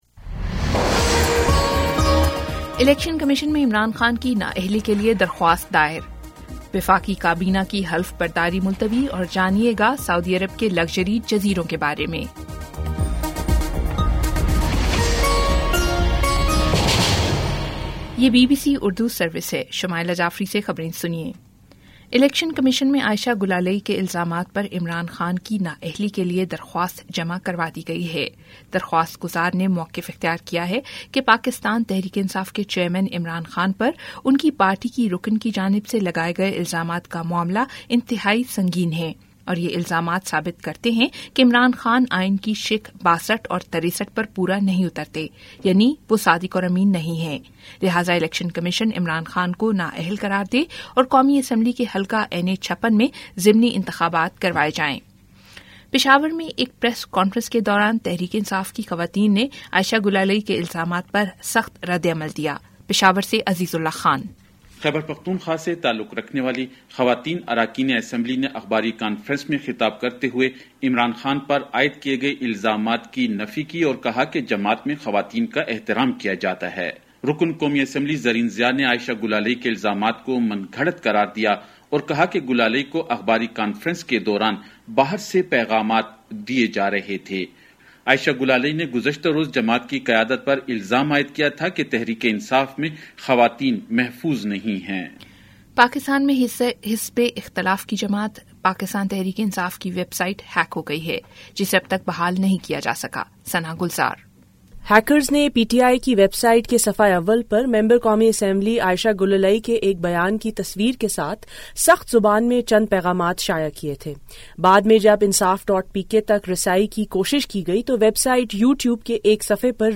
اگست 02 : شام سات بجے کا نیوز بُلیٹن